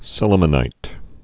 (sĭlə-mə-nīt)